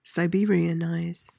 PRONUNCIATION:
(sy-BEE-ree-uh-nyz)